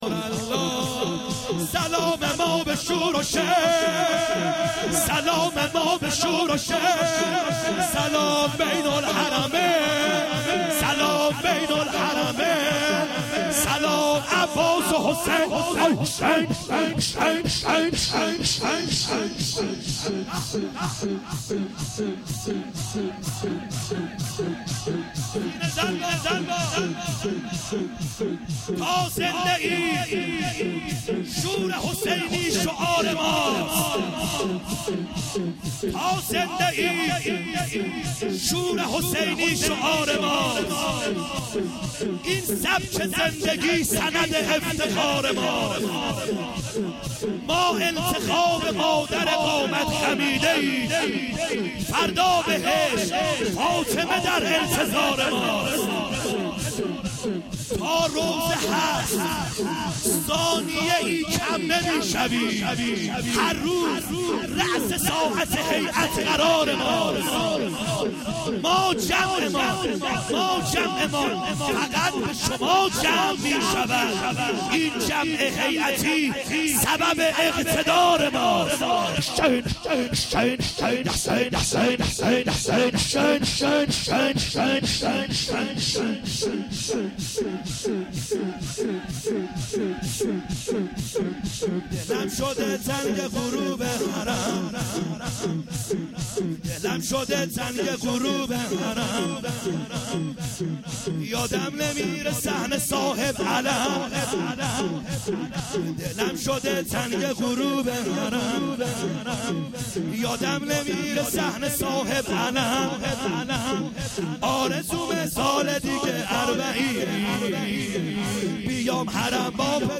خیمه گاه - بیرق معظم محبین حضرت صاحب الزمان(عج) - شور | دلم شده تنگ غروب حرم